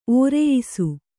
♪ ōreyisu